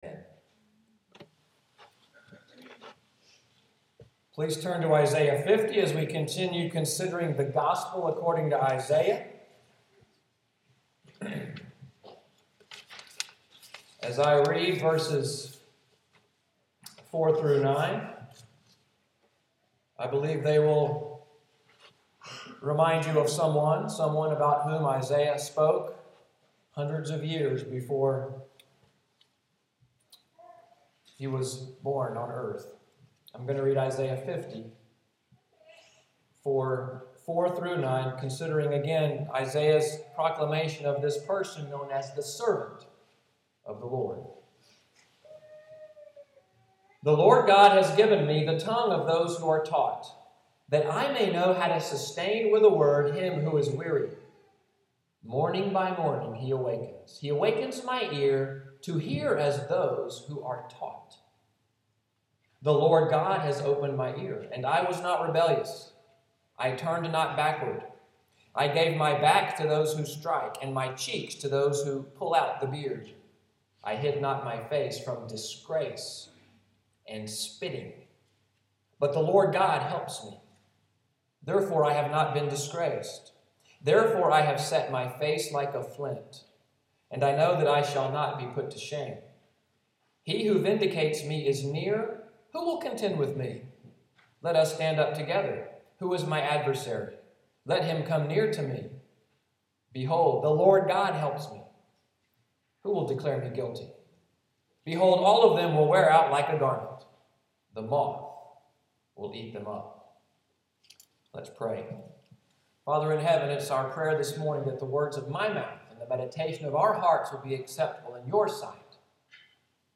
Sermon Text: Isaiah 50:4-11 Sermon outline & Order of worship